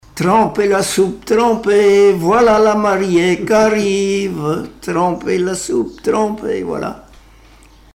Chansons et témoignages
Pièce musicale inédite